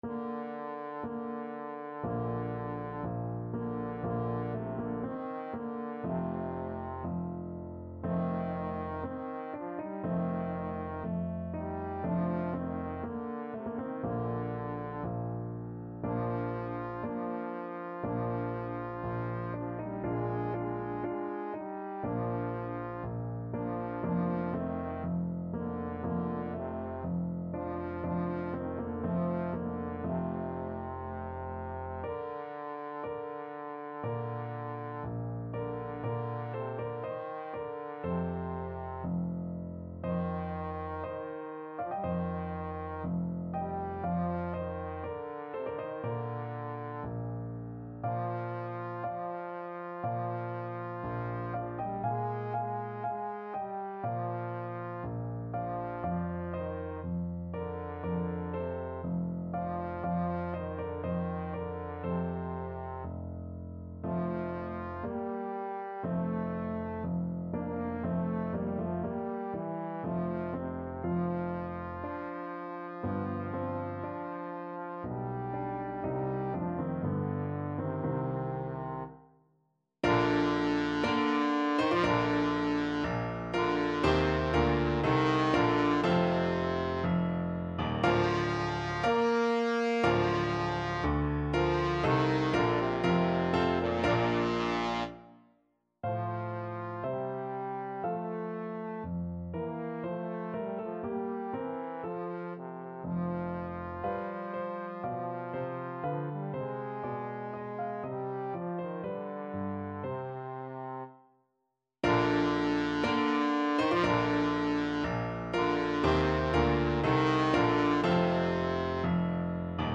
Free Sheet music for Trombone
Bb major (Sounding Pitch) (View more Bb major Music for Trombone )
Slow =c.60
4/4 (View more 4/4 Music)
Bb3-Bb4
Classical (View more Classical Trombone Music)
handel_saul_dead_march_TBNE.mp3